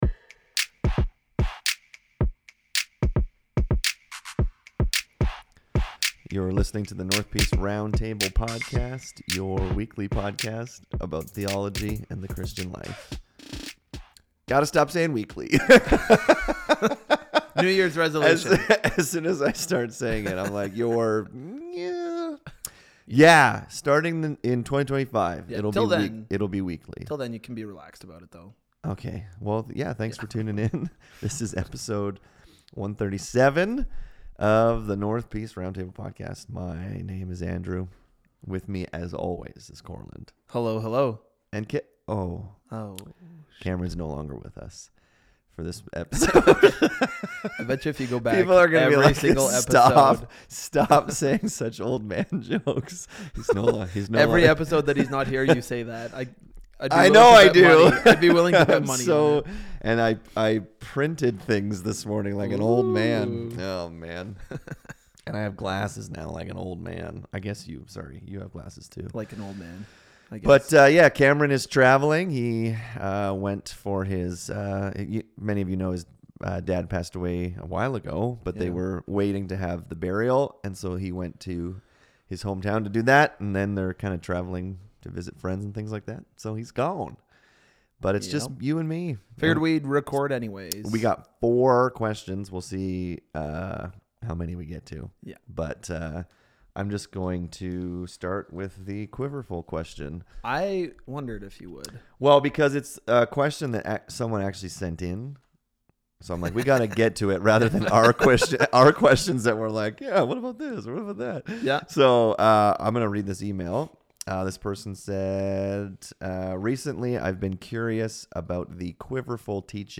In this episode the guys answer two questions from listeners. First, what do we think of the 'quiverful' teaching found in Psalm 127?